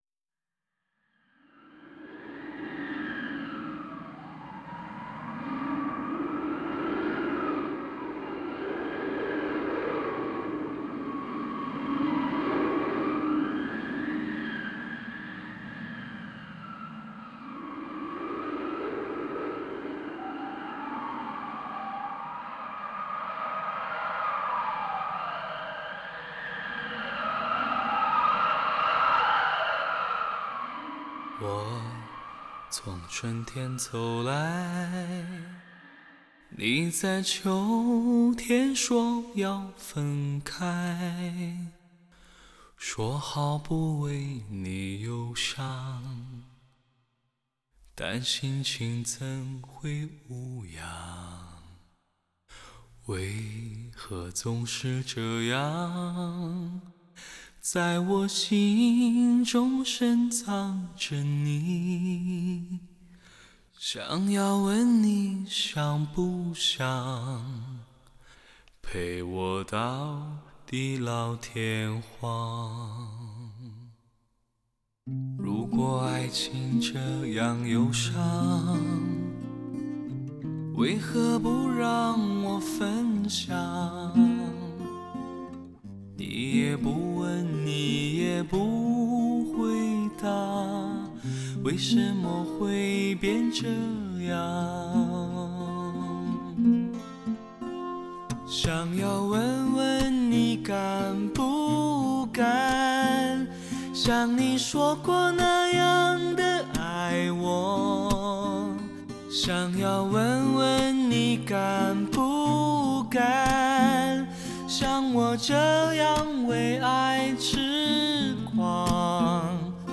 此专辑作为6.1DTS-ES测试碟，的确可圈可点。